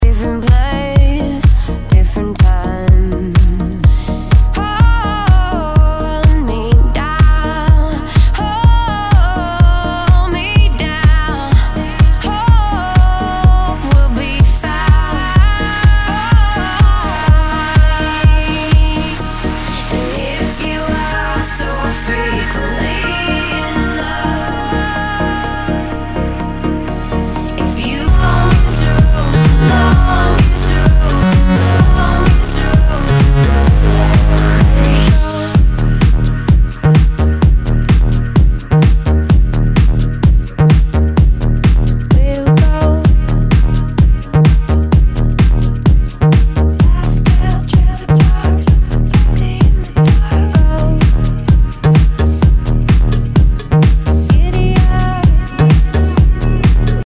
HOUSE/TECHNO/ELECTRO
ナイス！プログレッシブ・ヴォーカル・ハウス！